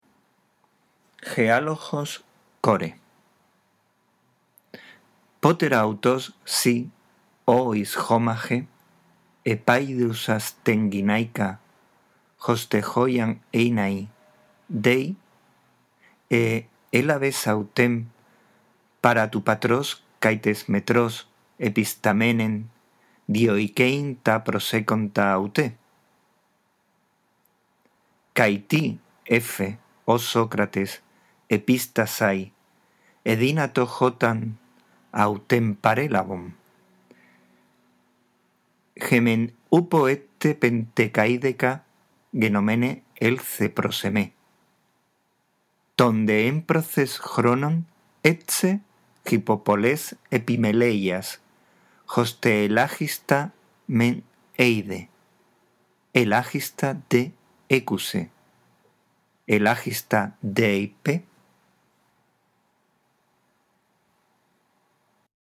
Este archivo de audio con la lectura del texto griego te ayudará a mejorar tu lectura